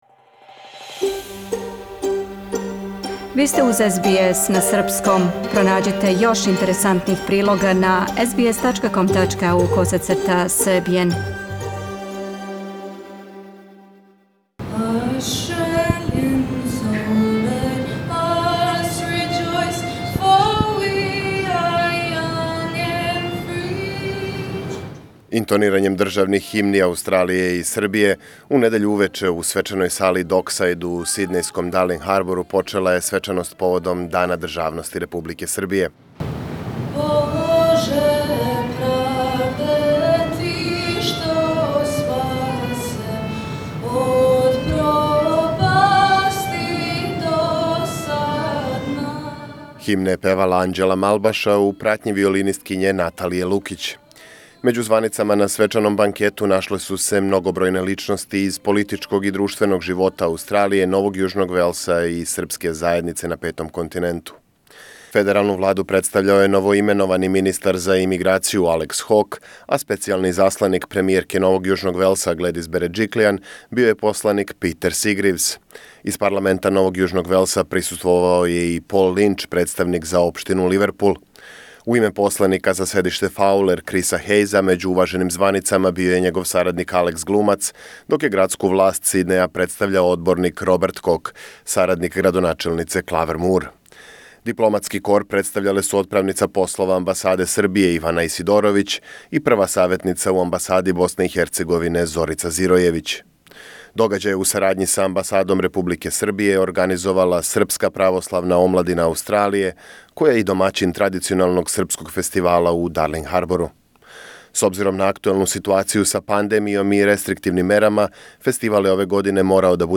Federal minister for immigration Alex Hawke speaks during the Serbian Statehood Banquet in Sydney Source